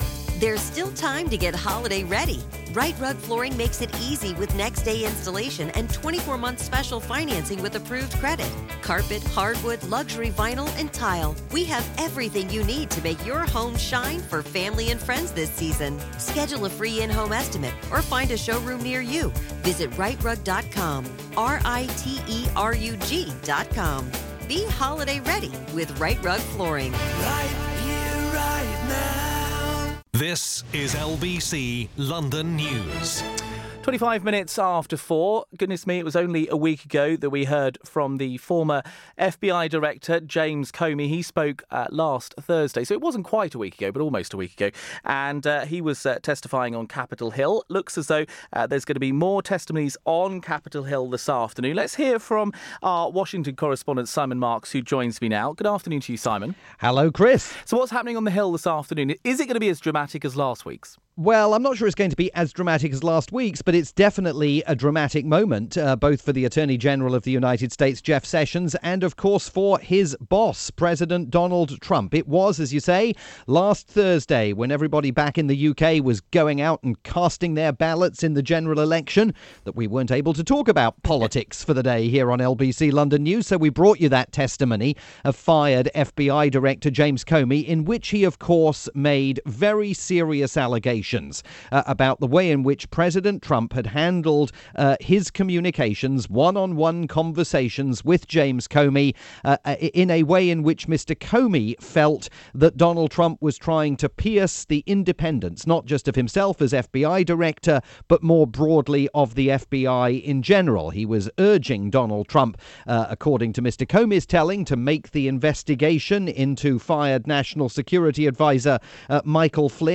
via the UK's rolling news station LBC London News.